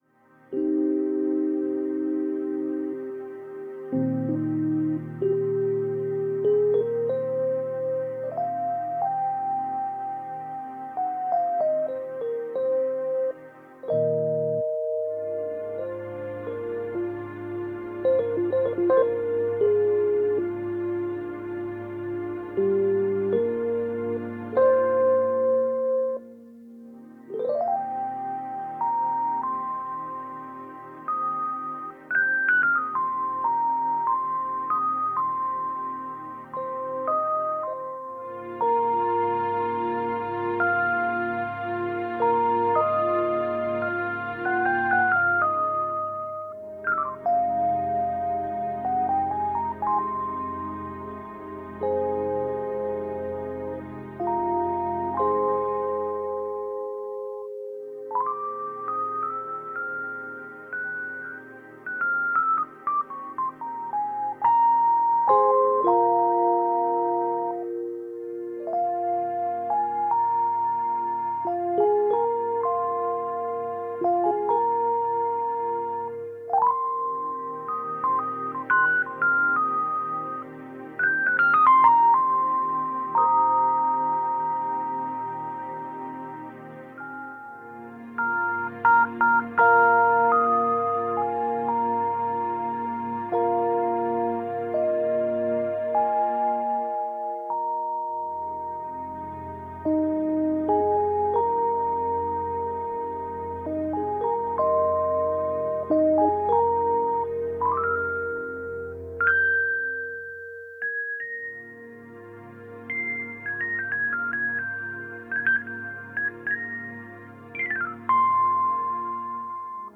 Relax Synth.